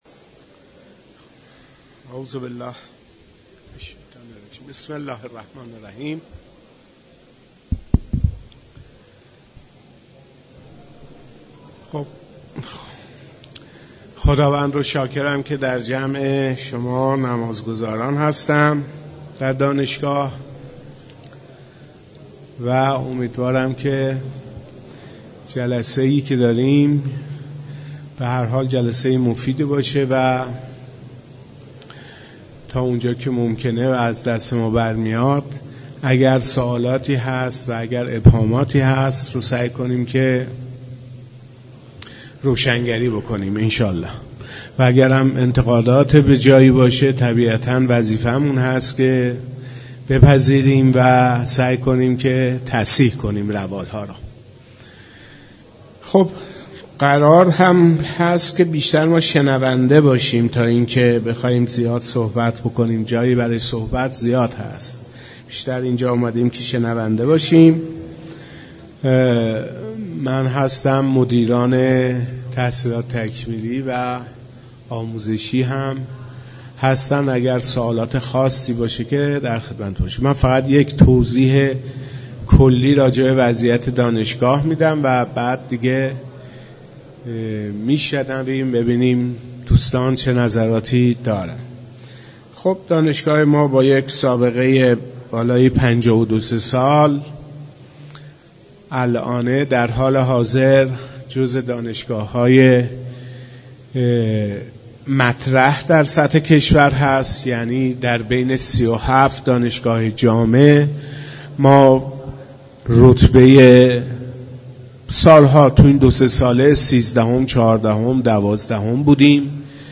جلسه تبیین برنامه های آموزشی دانشگاه در مسجد دانشگاه کاشان برگزار گردید